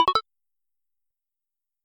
SFX_UI_Saved.mp3